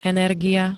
energia [-n-] -ie pl. G -ií D -iám L -iách ž.
Zvukové nahrávky niektorých slov